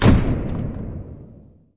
boom.wav